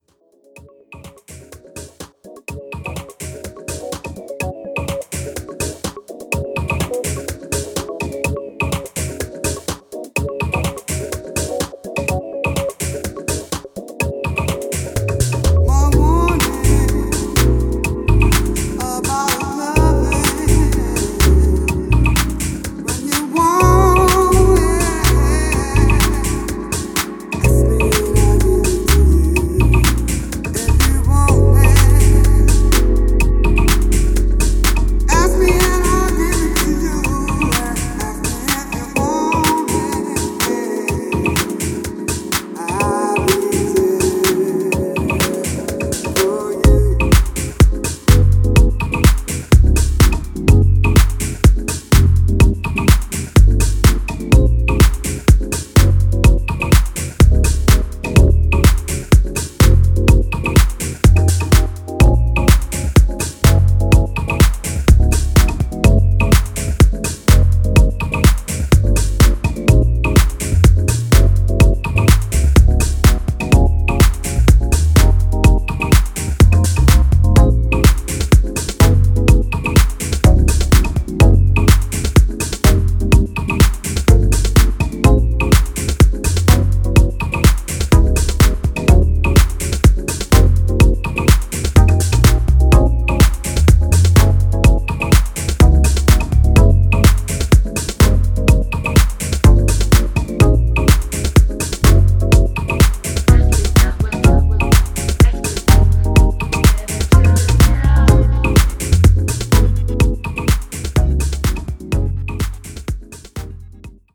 smooth vocals